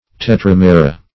Search Result for " tetramera" : The Collaborative International Dictionary of English v.0.48: Tetramera \Te*tram"e*ra\, n. pl.
tetramera.mp3